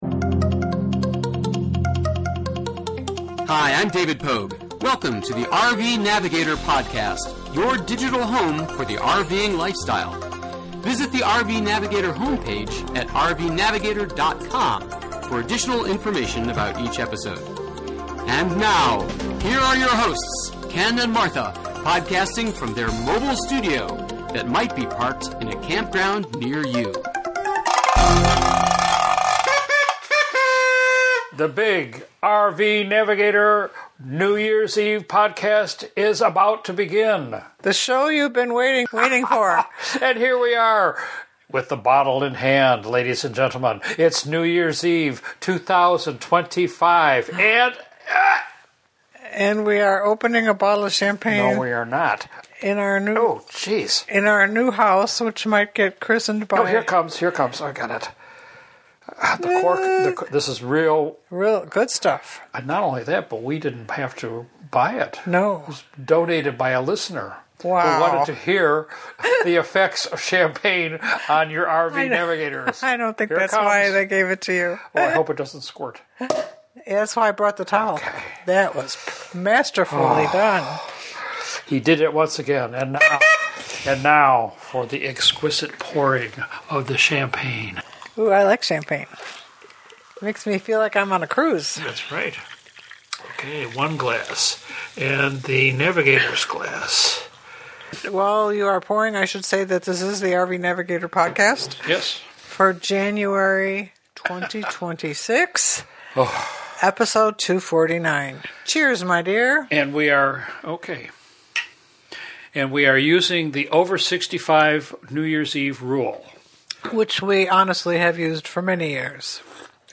This episode is our annual NYE episode that includes some champaign and noise makers - so beware of unexpected frivolity and loud sounds as we ring in the new year in style.